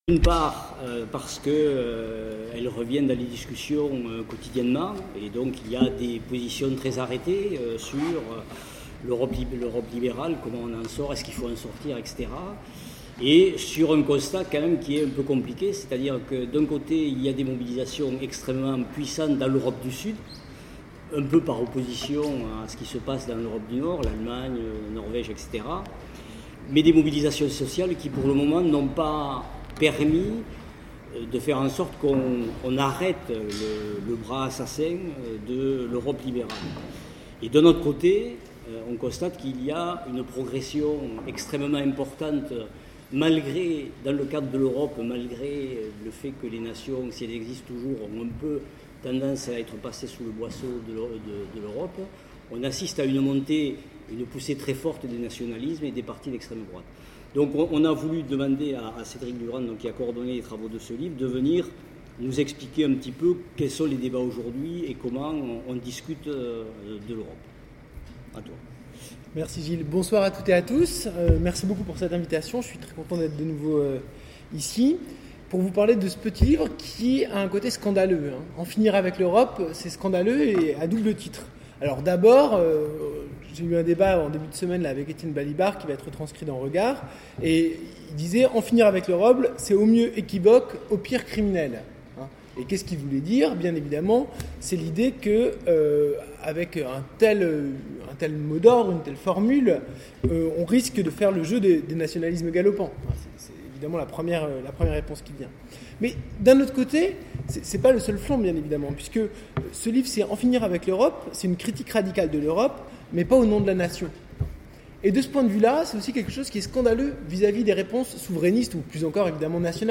Université Populaire de Toulouse, 23 mai 2013